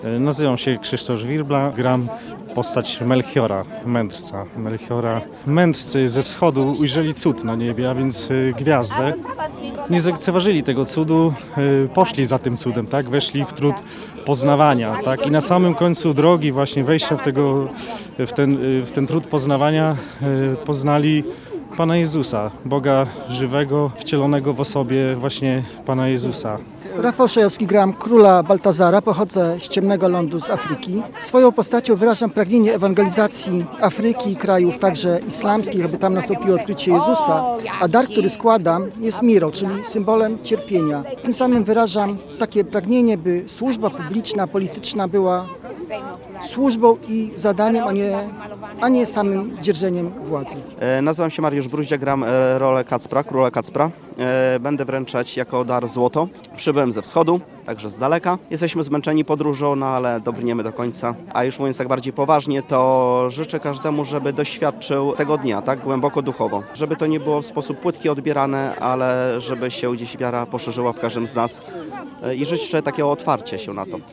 Kacper, Melchior i Baltazar ze swoją świtą przemaszerowali w sobotę (6.01) ulicami Ełku.